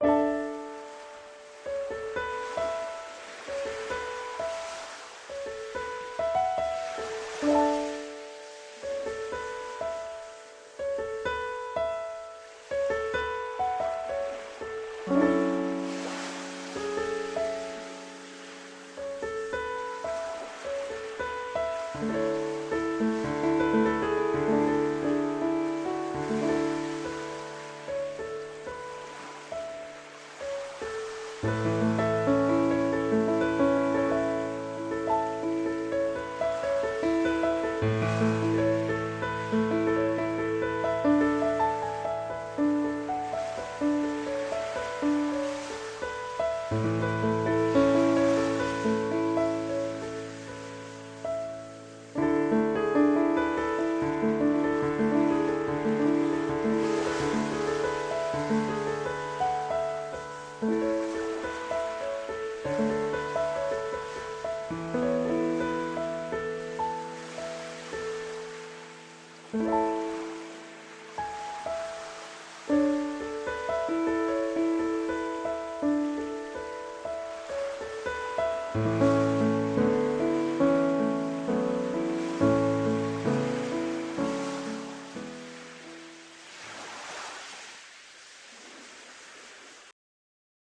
一人声劇「星の砂浜」